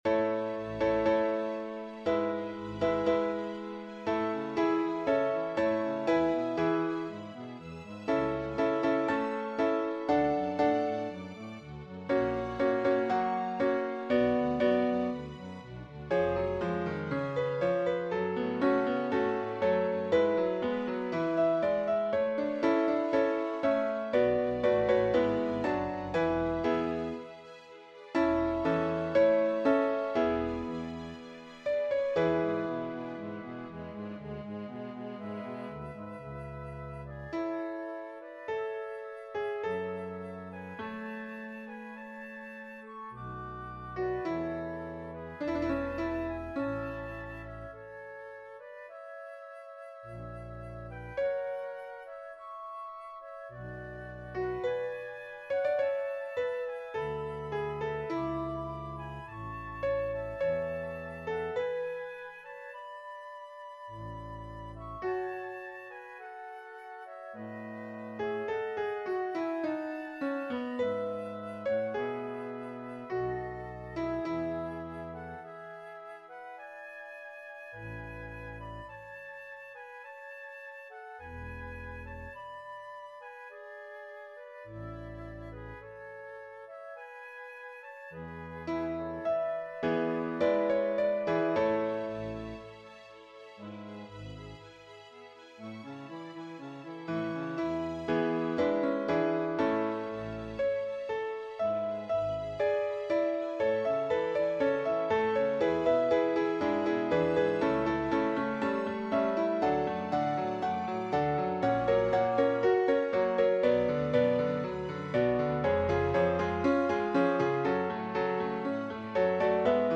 MP3 Dateien von allen Chorstücken nach Register
📄 1_MP3/_SATB/BWV234-2 Gloria_60_SATB.mp3